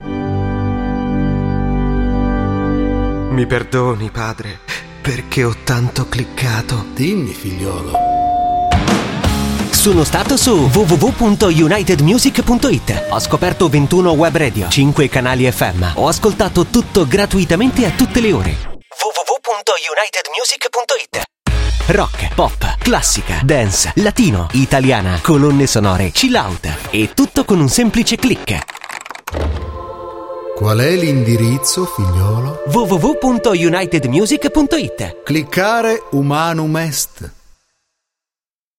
Sprecher italienisch.
Sprechprobe: Industrie (Muttersprache):